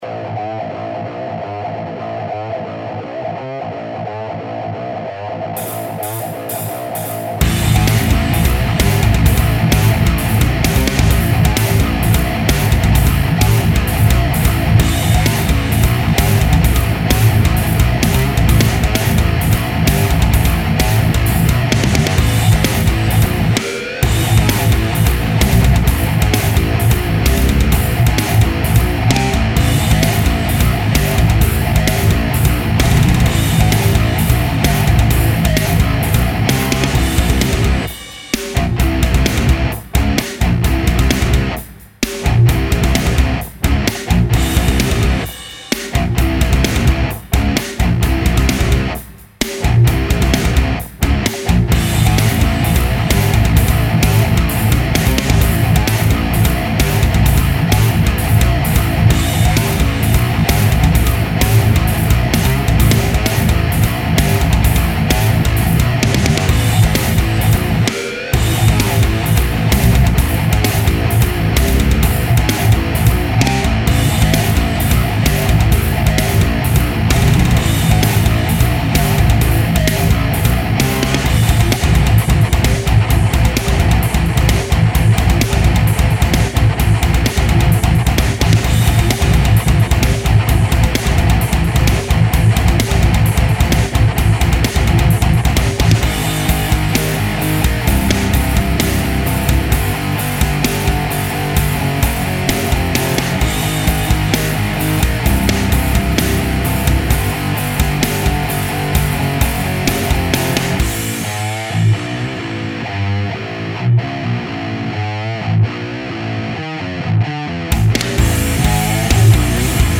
Un nouveau sample (j'essaie de faire sonner le pod hd pour jouer du djent, mais c'est pas simple, les palm mutes generent beaucoup trop de basse...), dans le plus pur style djent